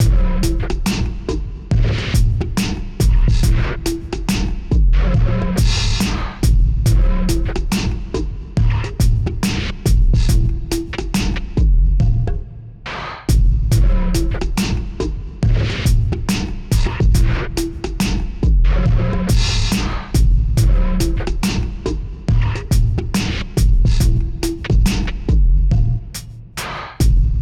drum3.wav